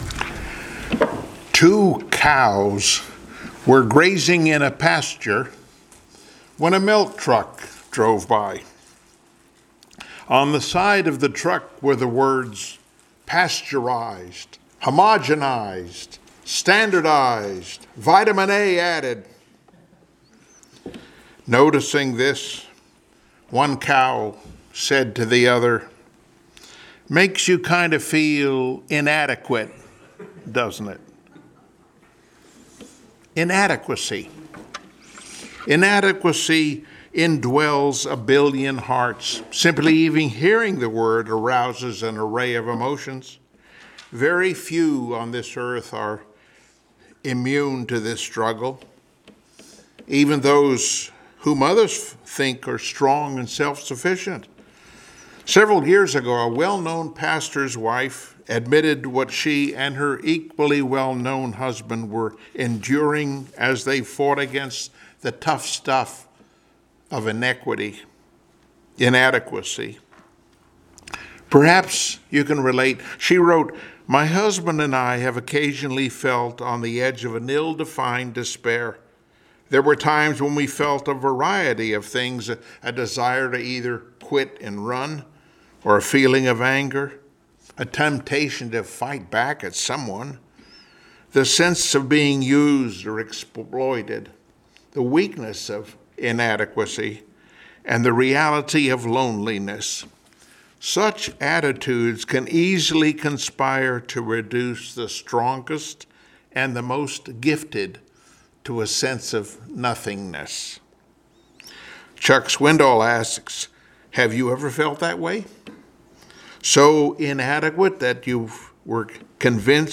Service Type: Sunday Morning Worship 2 Corinthians 12:7-10 Topics: Paul: Legalist and self-hating , Sara;Hagar , What inadequacy forces us to do « “Shame” “Death” »